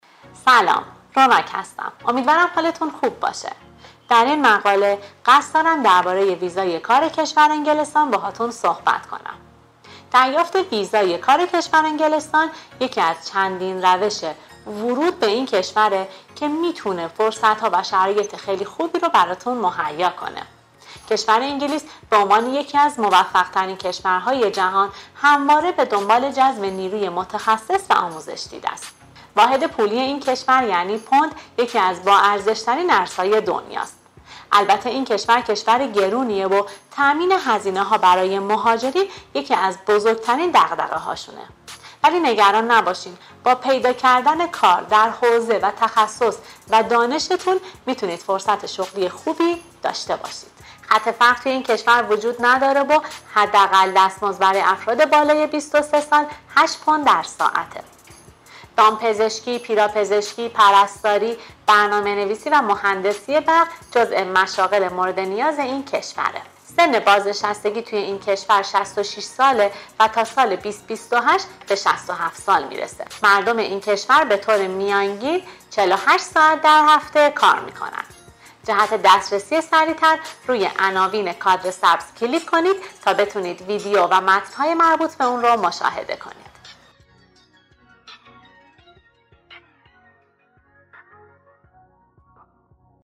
پادکست کار در انگلستان